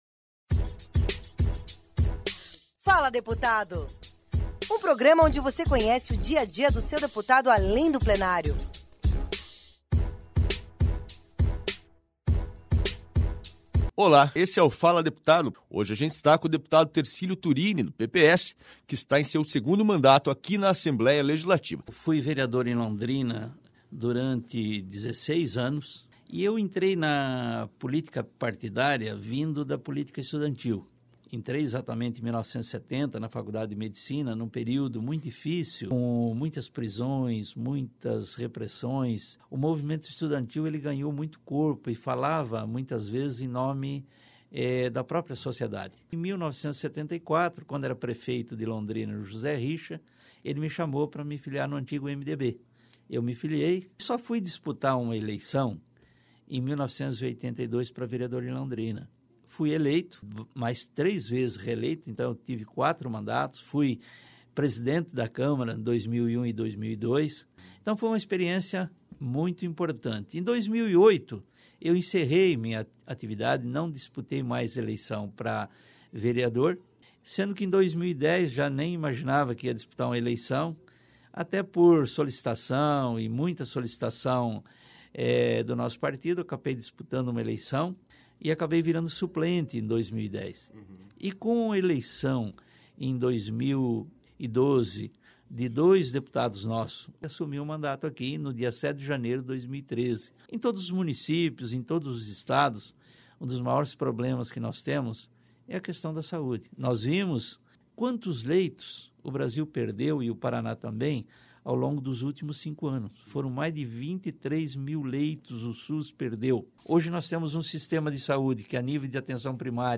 “Sou um sobrevivente”, afirmou Tercílio Turini em entrevista ao programa “Fala Deputado” desta semana A história de vida deste médico e hoje parlamentar da região de Londrina vai emocionar você!